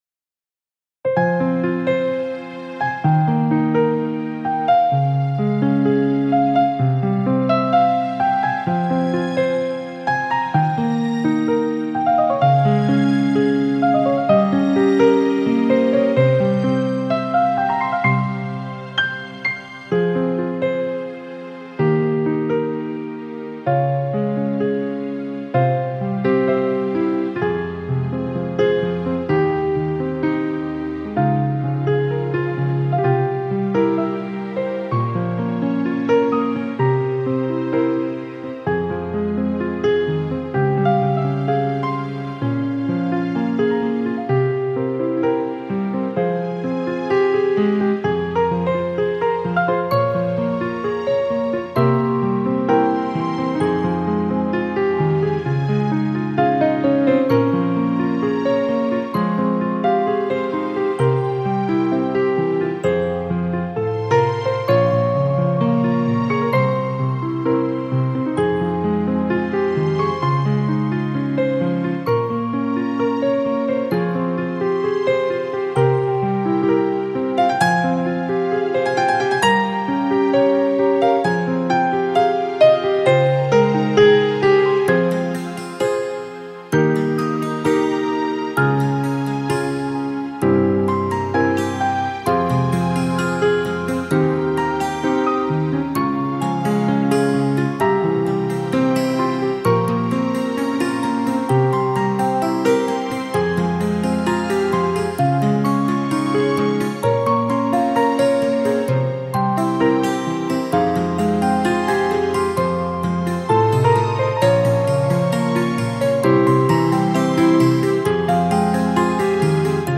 Скачать христианскую музыку и фонограммы.